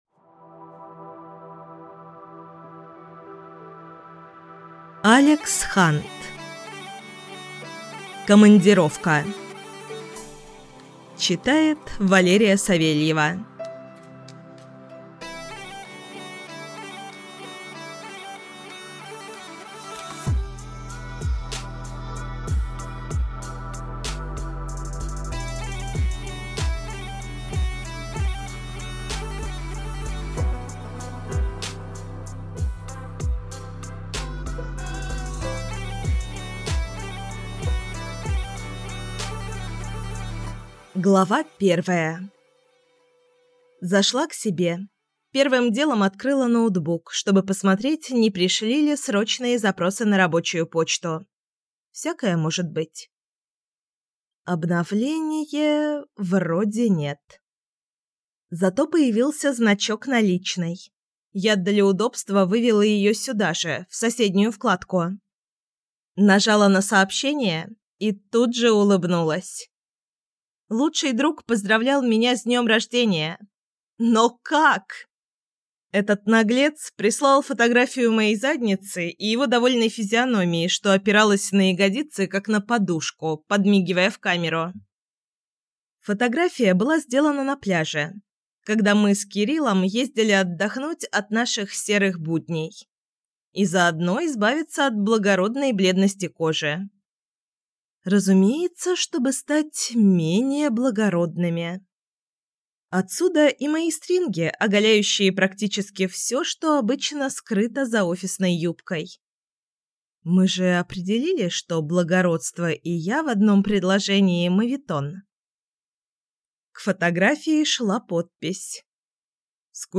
Аудиокнига Командировка | Библиотека аудиокниг
Прослушать и бесплатно скачать фрагмент аудиокниги